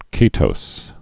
(kētōs)